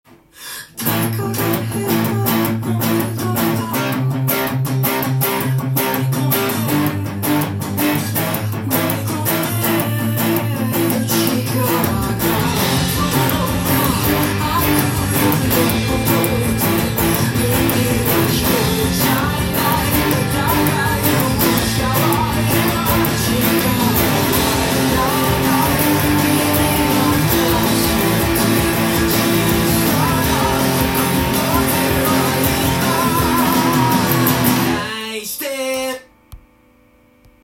音源に合わせて譜面通り弾いてみました
基本的なリズムパターンは「ジャ　ズズ、ジャ　ズズ、ジャ　ズ」
ズズのところは、ブリッジミュートをします。